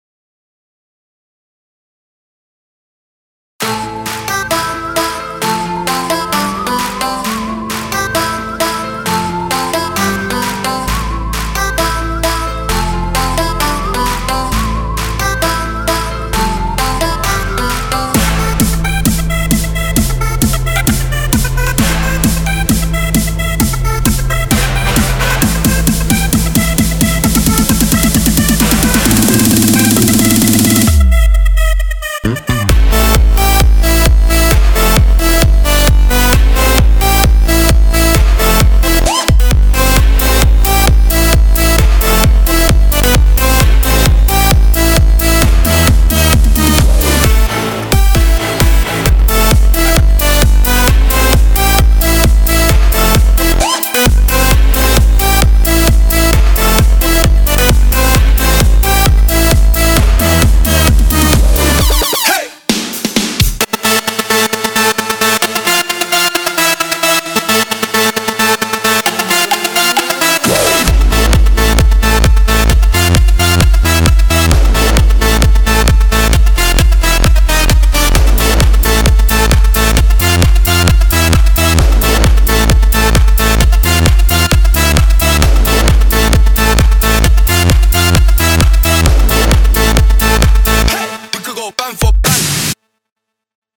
רק מפריע לי הסיידצ’ן. מידי חד ומידי ארוך. הוא משתיק את הכל למשך חצי פעימה - עד 192 אם אתה מכיר תעסק…